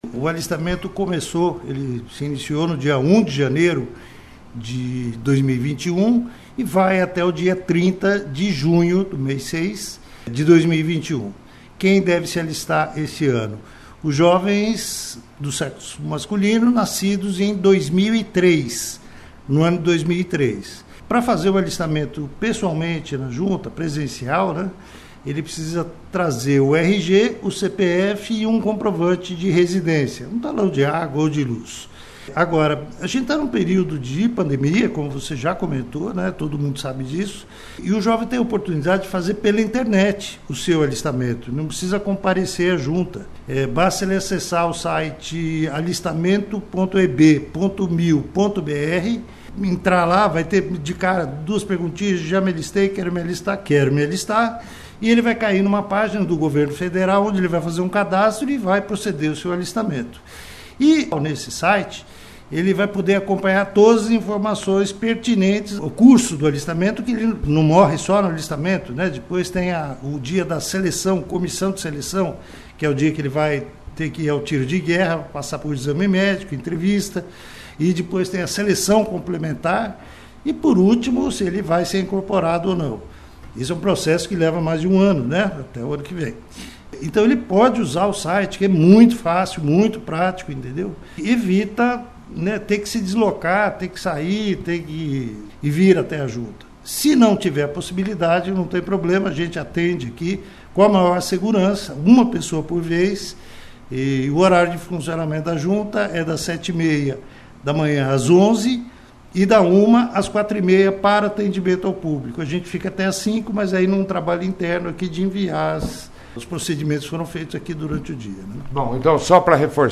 participou da 1ª edição do jornal Operação Cidade desta sexta-feira